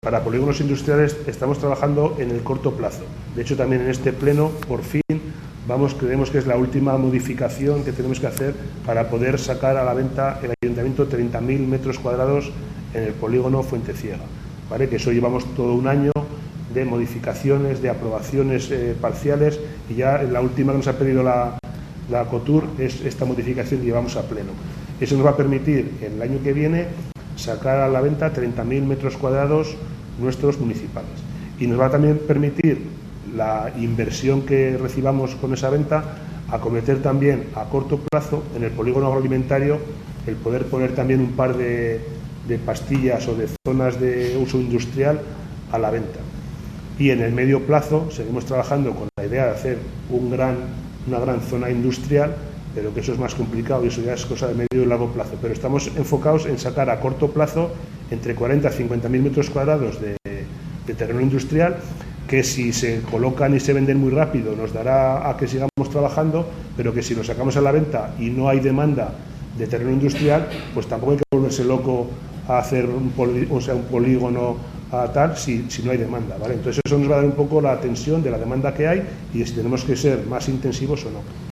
El concejal de Economía, Rafael García, a preguntas de RADIO HARO, ha insistido en el anuncio que hizo el pasado mes de marzo.